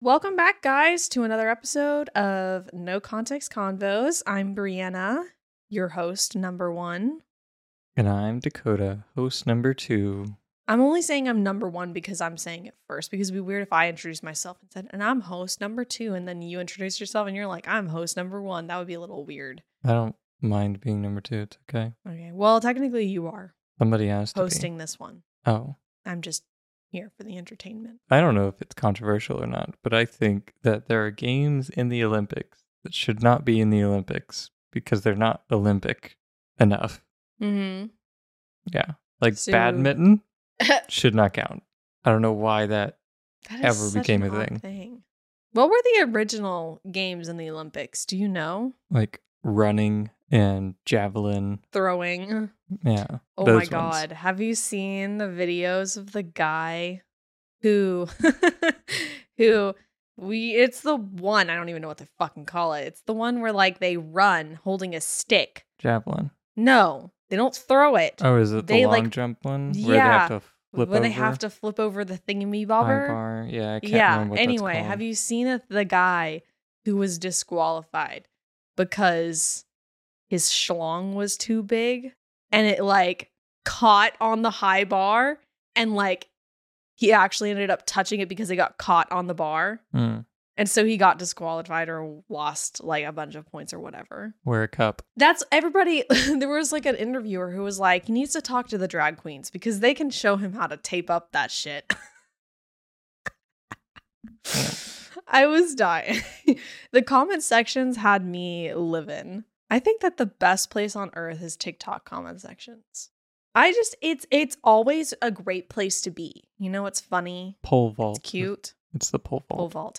Play Rate Apps Listened List Bookmark Share Get this podcast via API From The Podcast No Context Convos 1 Laugh along with this witty husband and wife duo as they banter and riff on all aspects of life, relationships, current events, and more on No Context Convos.
Expect games, stories, and unfiltered chats where no topic is off limits.